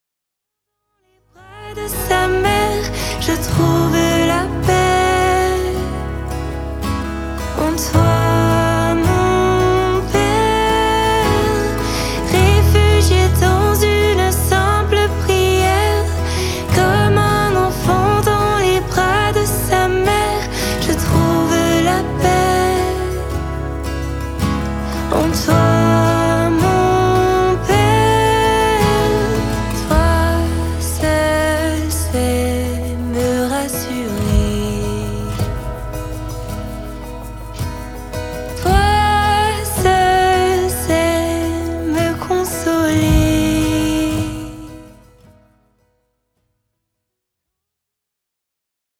avec des mélodies apaisantes et une louange réconfortante.
ce sont 4 titres frais, doux et joyeux